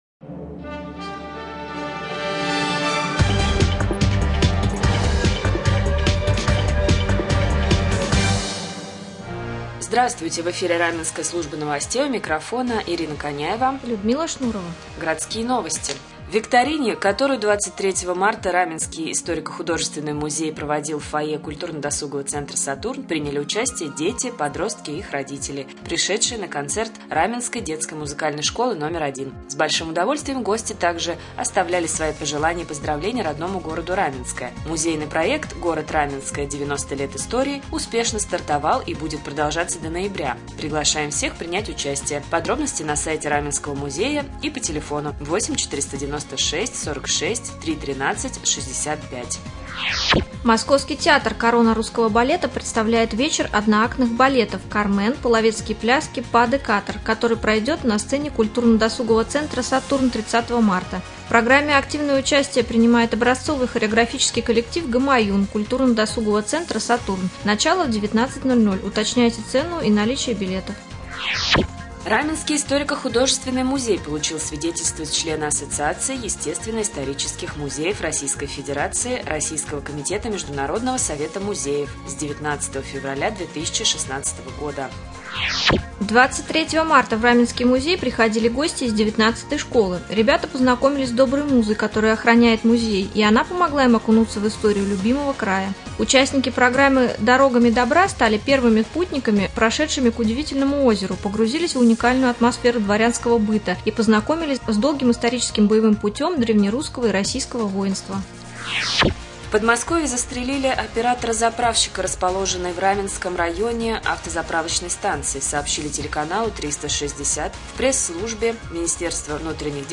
1. В прямом эфире генеральный директор Раменского водоканала Алексей Владимирович Демин. 2.
1.Прямой эфир. гость студии депутат Совета депутатов г.п.Раменское, генеральный директор Раменского водоканала Алексей Владимирович Демин.